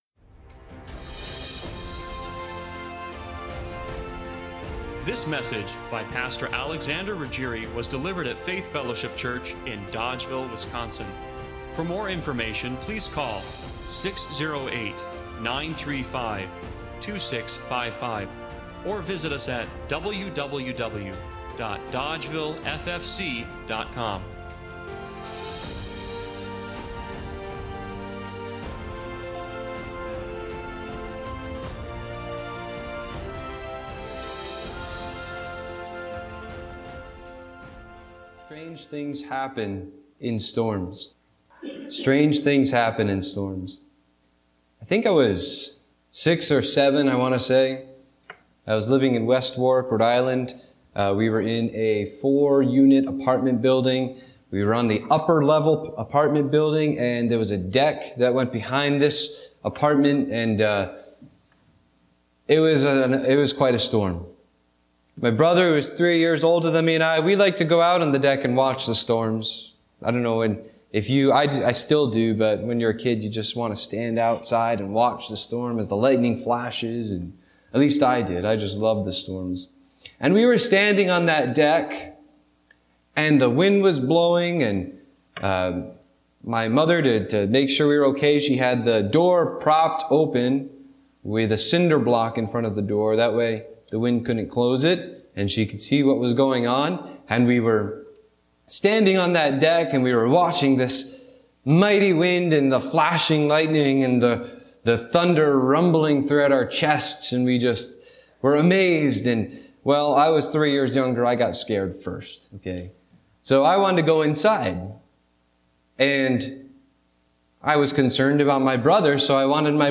Luke 8:22-25 Service Type: Sunday Morning Worship Where is your faith?